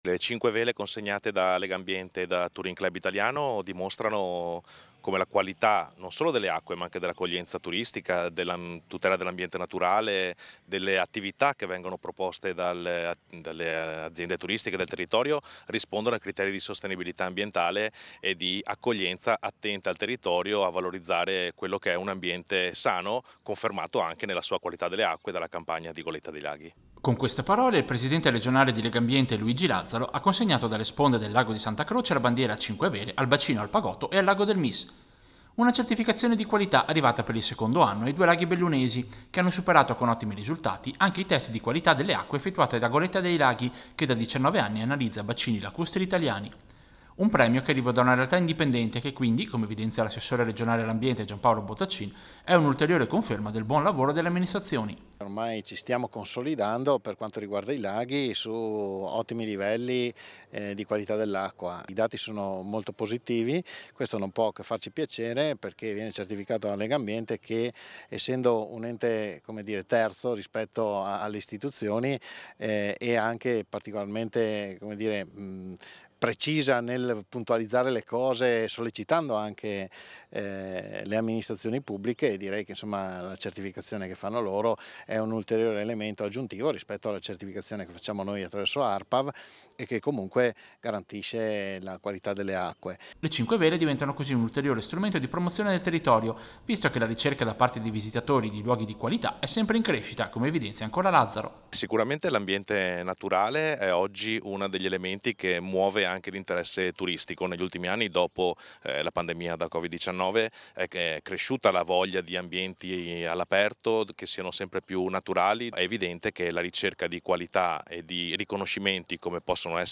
Servizio-Goletta-dei-Laghi-2024.mp3